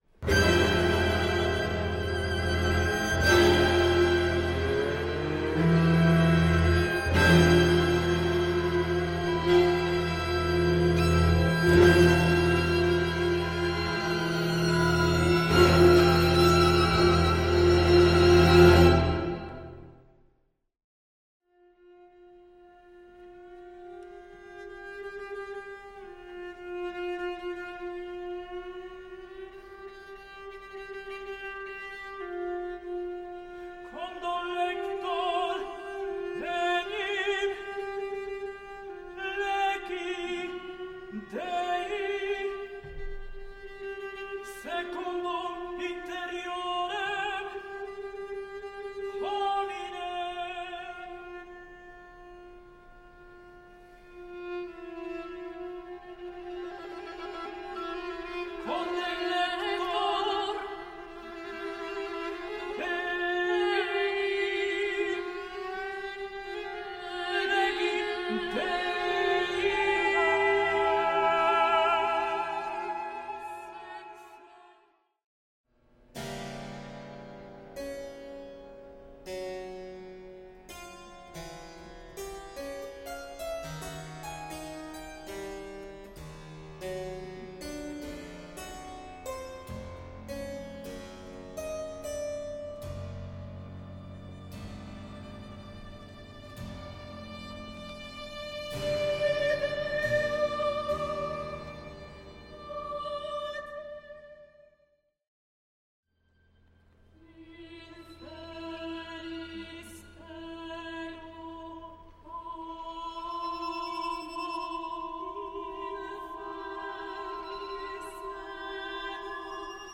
for choir, harpsichord and strings
harpsichord